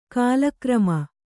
♪ kālakrama